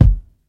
• Steel Kick Drum Sample F Key 309.wav
Royality free kickdrum sound tuned to the F note. Loudest frequency: 113Hz
steel-kick-drum-sample-f-key-309-DKF.wav